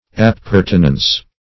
Meaning of appertinence. appertinence synonyms, pronunciation, spelling and more from Free Dictionary.
appertinence.mp3